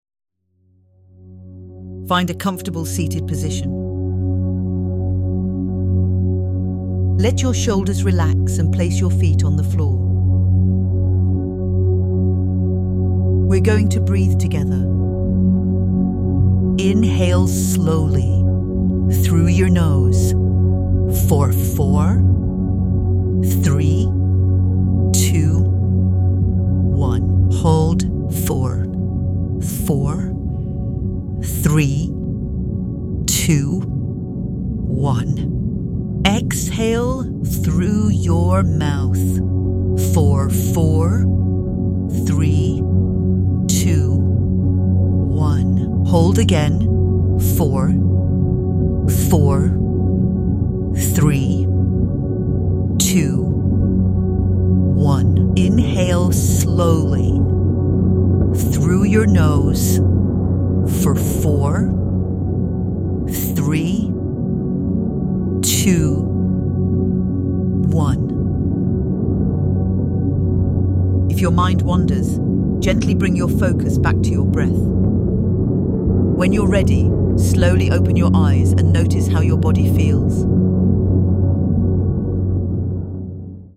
Guided-Breathing-Exercise.mp3